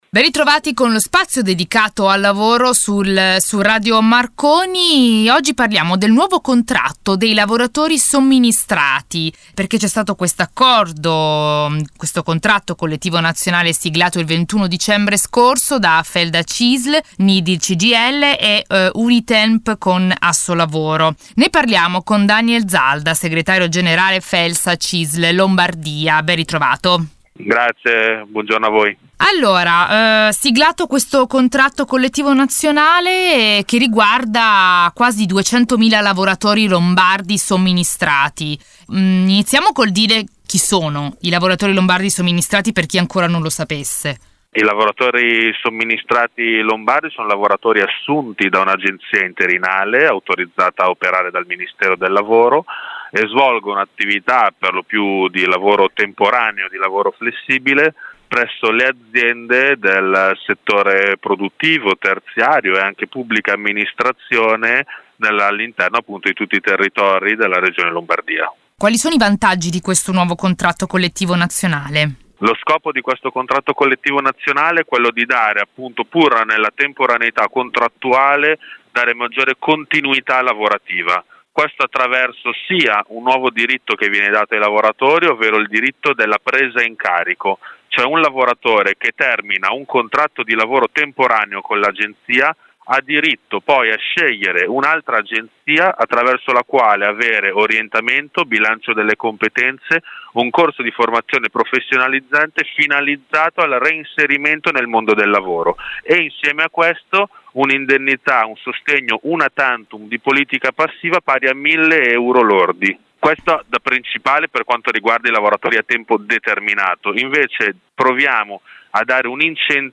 Questa settimana intervista